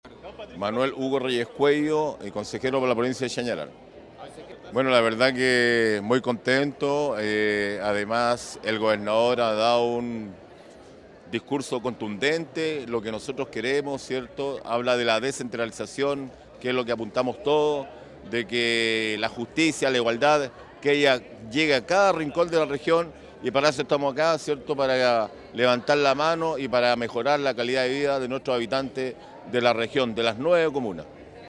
Manuel Reyes Cuello, consejero por Chañaral, resaltó el enfoque en descentralización «El gobernador ha dado un discurso contundente.
CONSEJERO-MANUEL-REYES-.mp3